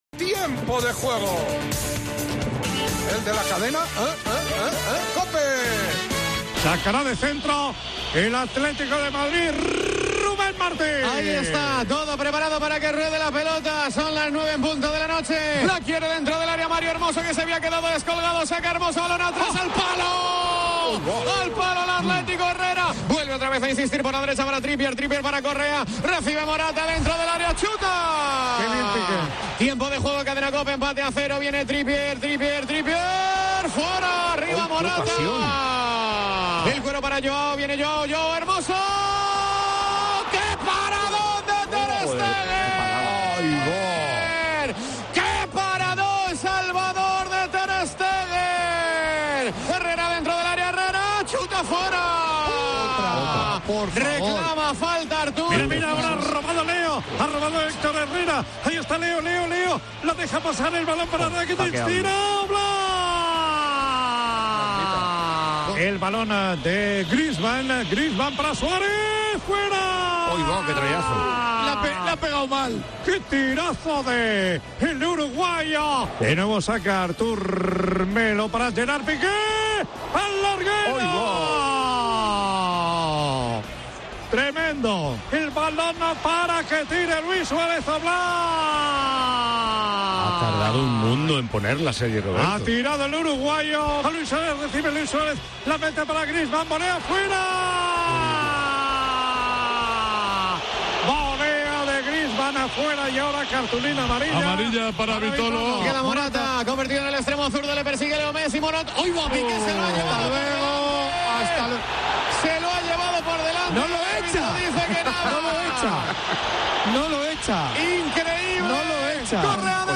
AUDIO: Escolta el resum del partit al Wanda Metropolitano amb narració de